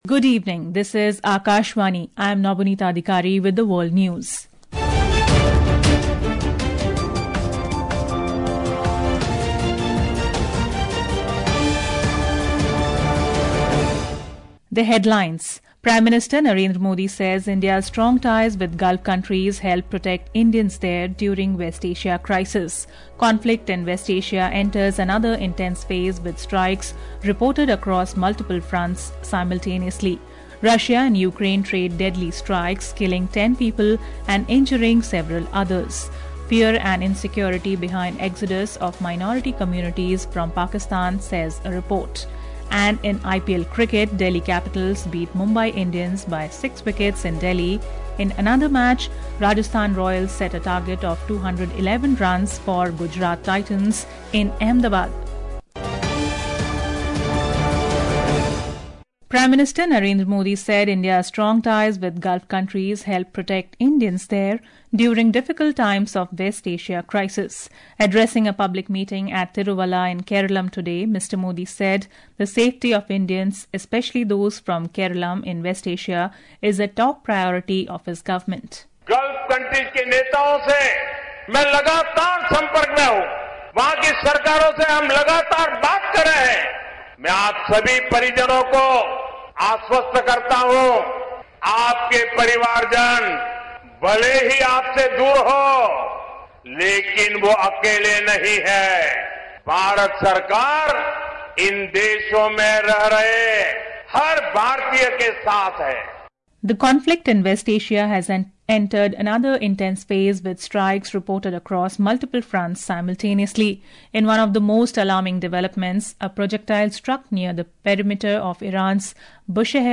world News
world-news.mp3